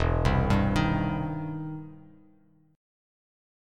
EmM7#5 chord